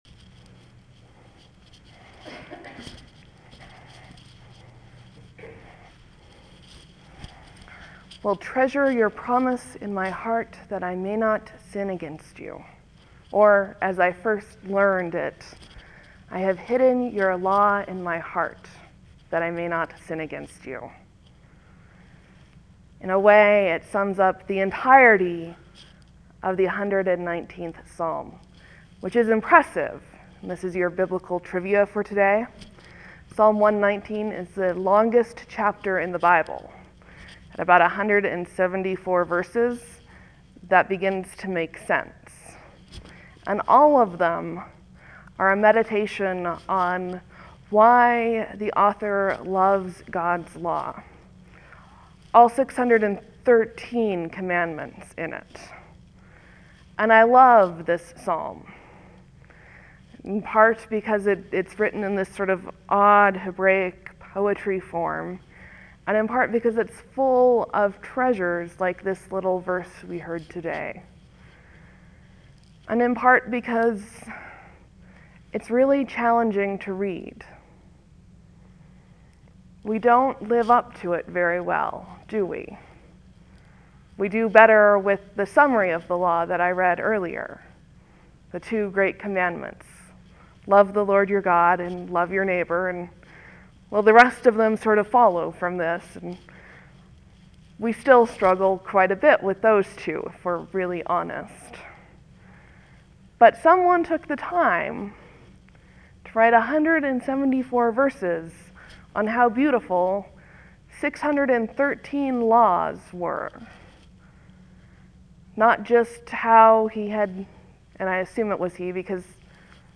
Lent, Sermon, , Leave a comment
(There will be a few moments of silence before the sermon starts. Thank you for your patience.)